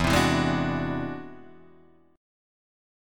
E Augmented 9th